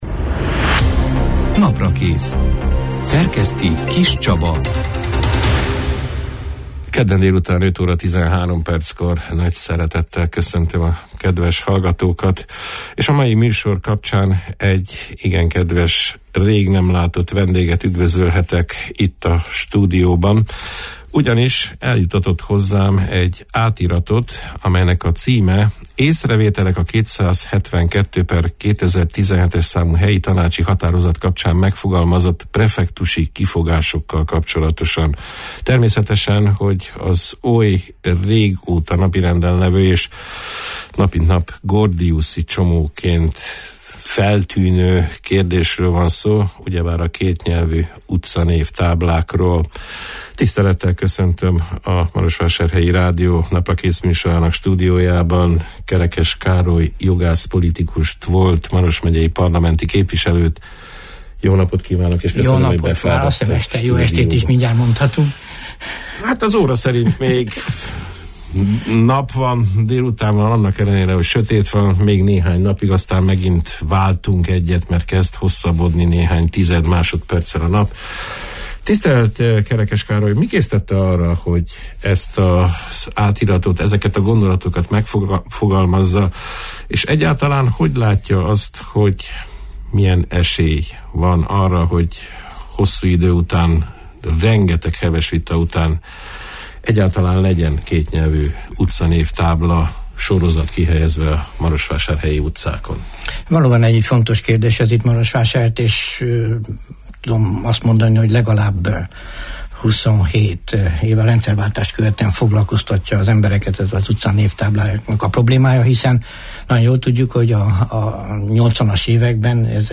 A részletekről, az elmúlt egy év alatt kifejtett tevékenységéről beszélgettünk a december 19 -én, kedden elhangzott Naprakész műsorban Kerekes Károly jogász – politikussal.